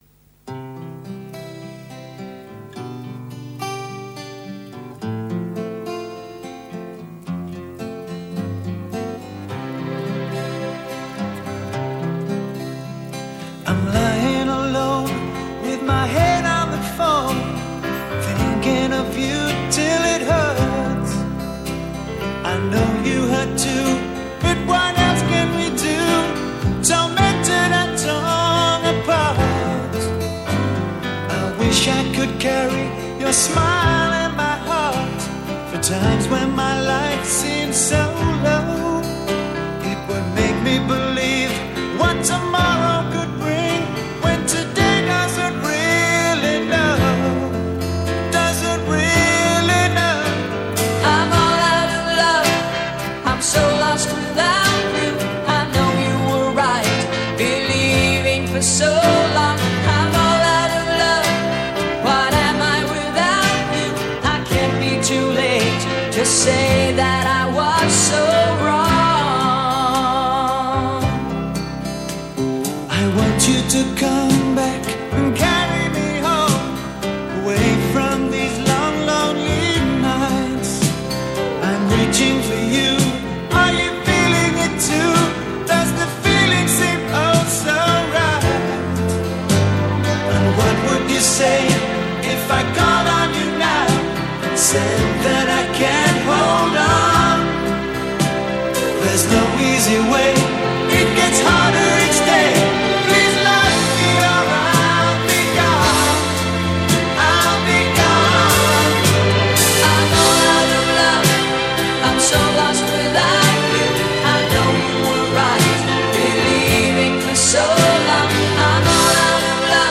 Pop Rock, Soft Rock